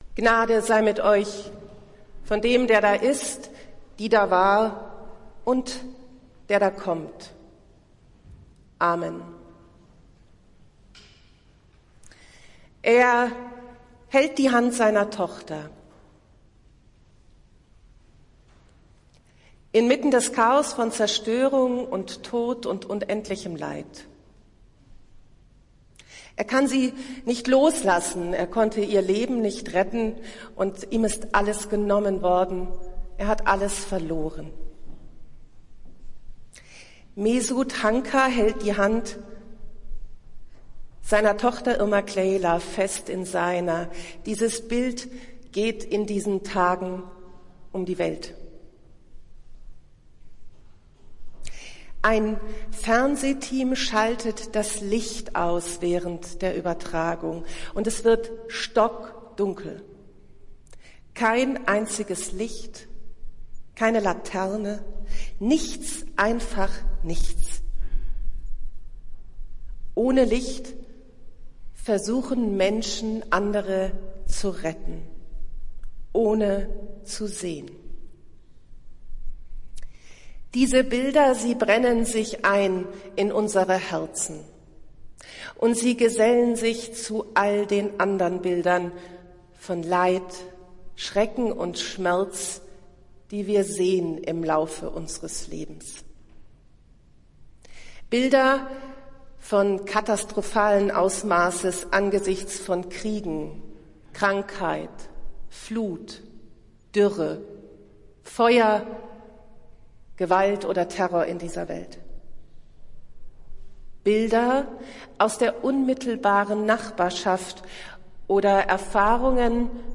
Predigt des Gottesdienstes aus der Zionskirche am Sonntag, den 12. Februar 2023
Wir haben uns daher in Absprache mit der Zionskirche entschlossen, die Predigten zum Nachhören anzubieten.